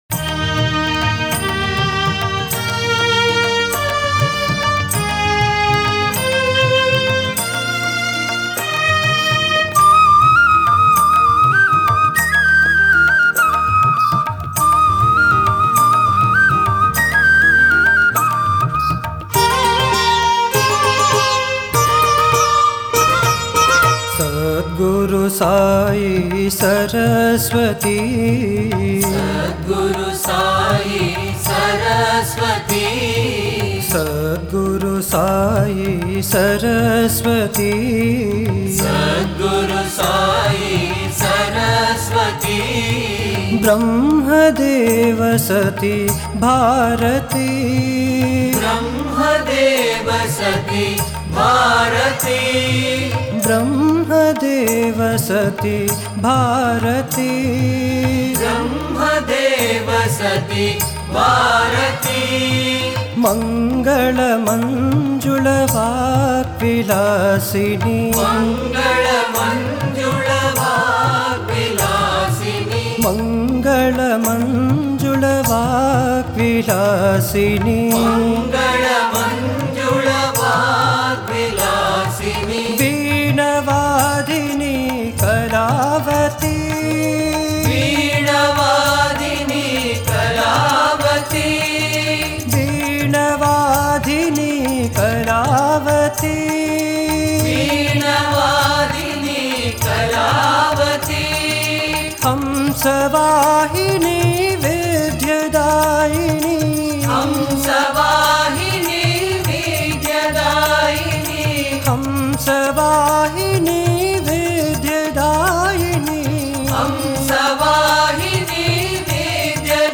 Author adminPosted on Categories Guru Bhajans